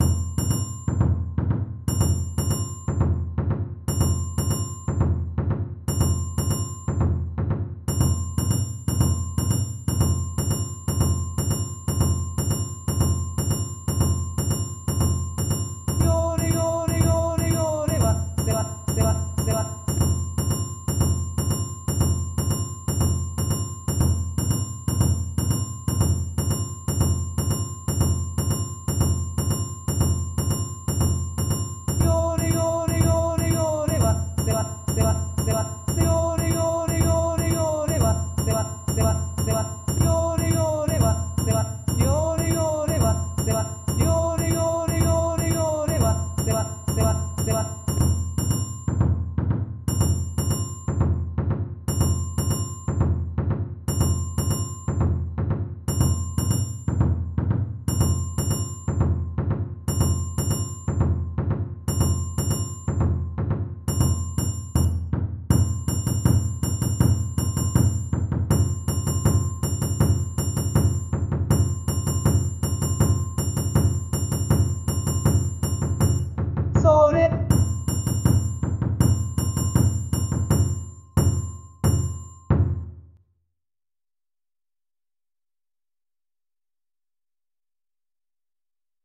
盆踊り